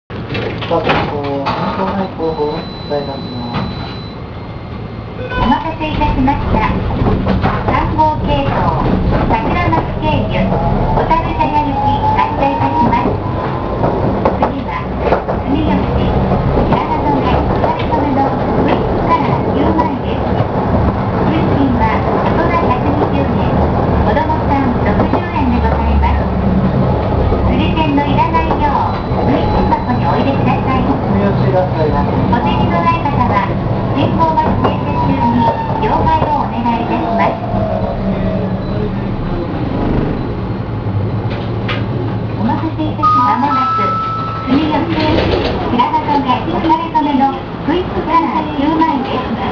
・3000形走行音
【赤迫支線】赤迫→住吉（49秒：270KB）
走行音自体はよく聞く東洋電機のIGBTなので特別面白い音ではありませんが、ドアチャイムがなかなか変わった音をしています。真ん中の車両には動力がない（というより台車すらない）ので、収録の際はご注意。